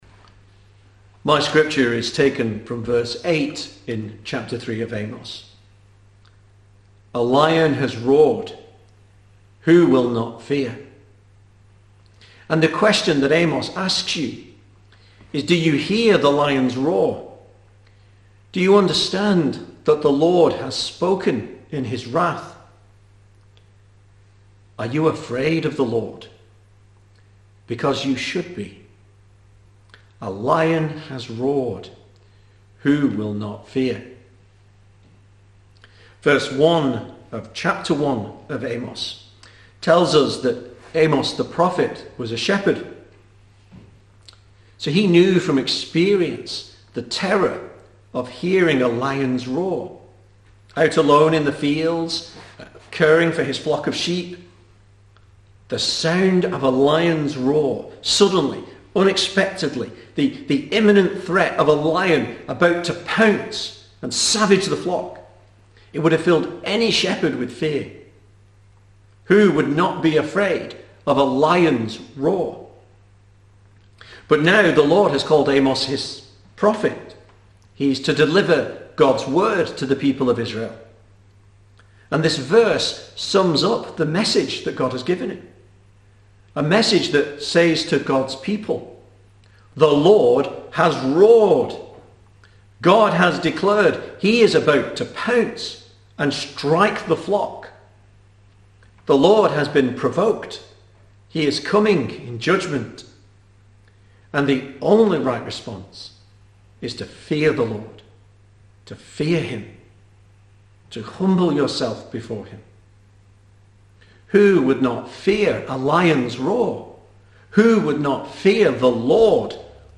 2020 Service Type: Sunday Evening Speaker